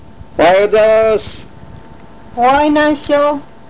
この町のことばをお聴きいただけます